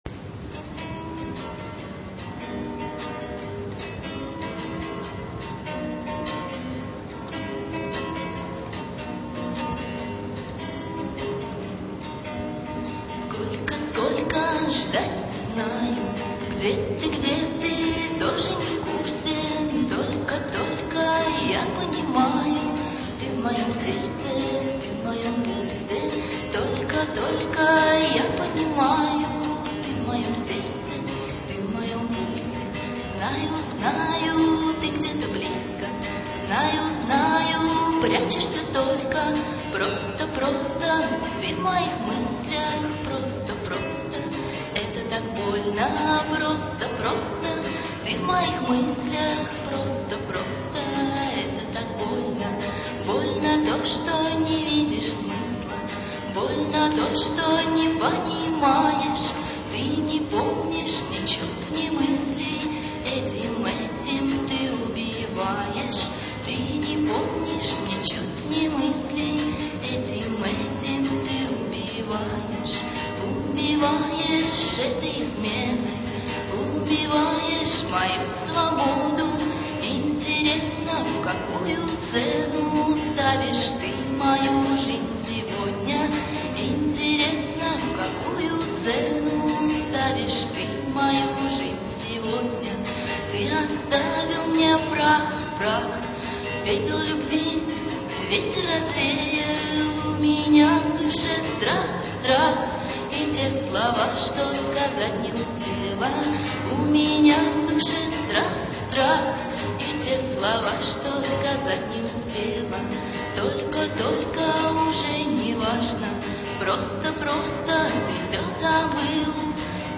Бардовский концерт
декабрь 2000, Гимназия №45
394 kb, авторское исполнение